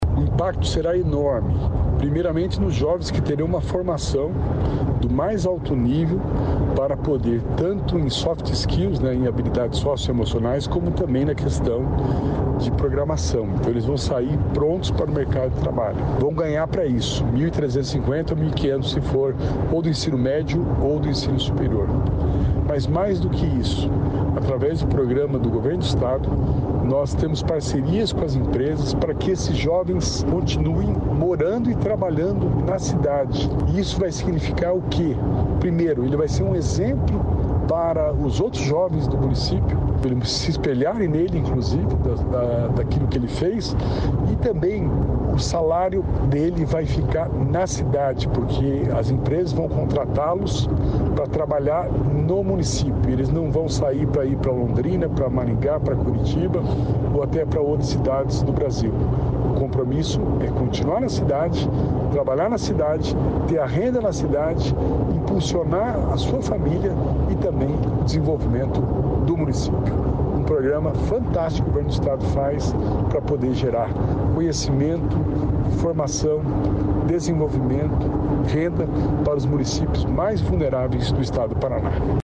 Sonora do secretário de Inovação, Modernização e Transformação Digital, Alex Canziani, sobre o Talento Tech-PR